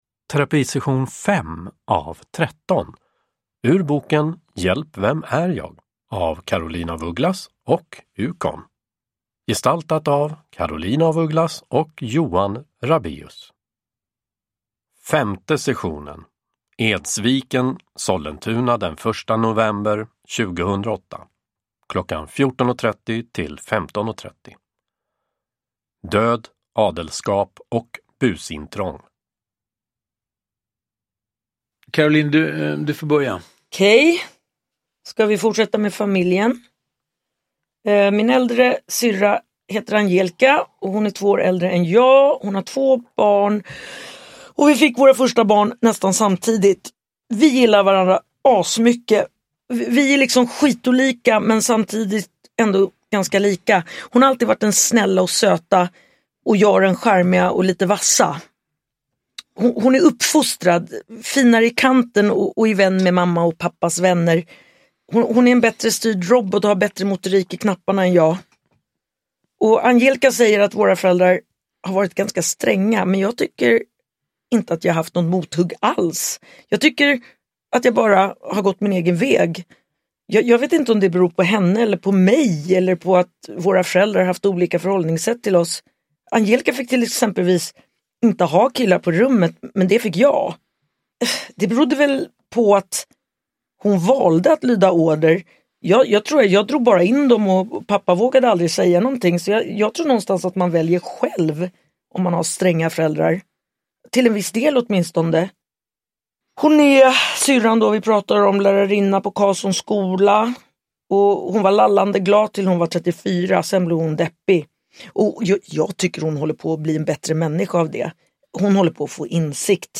Uppläsare: Johan Rabeus
Ljudbok